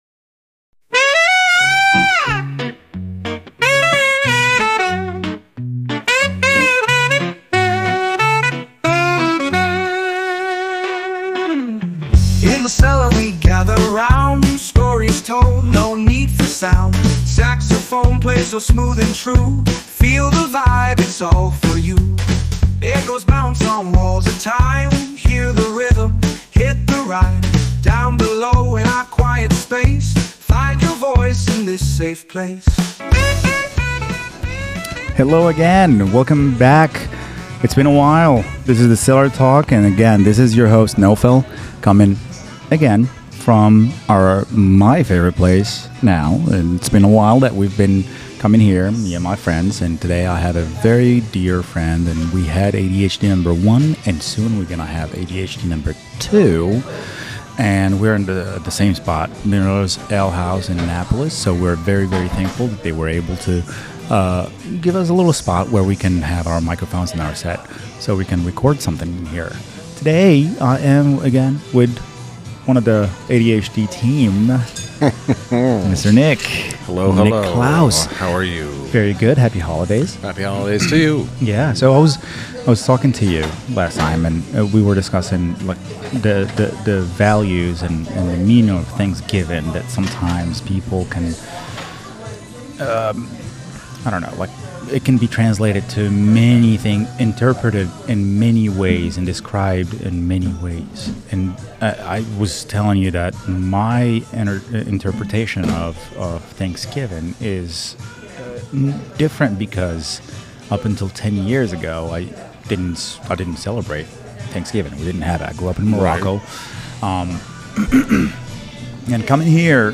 at Miller’s Ale House
a fun conversation about the holidays and what they mean to people. From personal traditions to quirky holiday stories, we explore the season’s expected and unexpected moments.